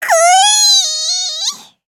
Taily-Vox_Casting3_jp.wav